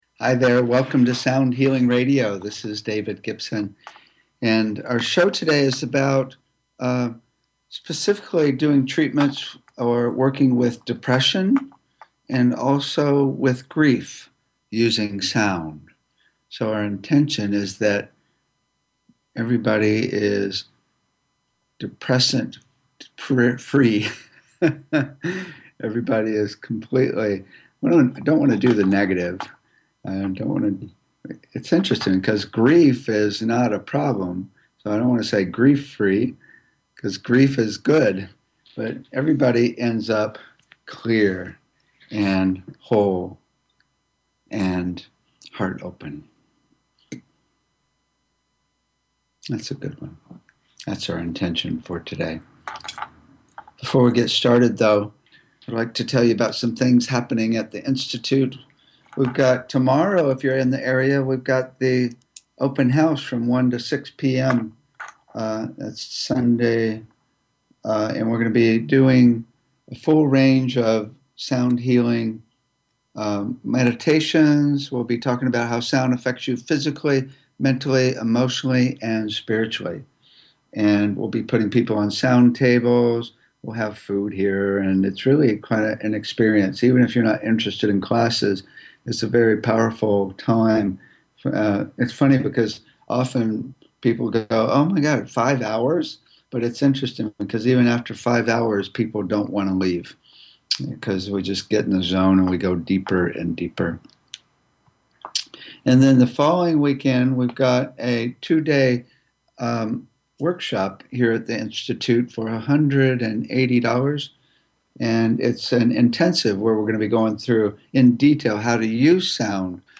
Talk Show Episode, Audio Podcast, Sound Healing and The Sounds of Grief and Loss on , show guests , about Grief and Loss,Sound Therapy,Using Sound to Heal, categorized as Health & Lifestyle,Energy Healing,Sound Healing,Kids & Family,Music,Philosophy,Science,Self Help,Spiritual